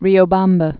(rēō-bämbə, -vämbä)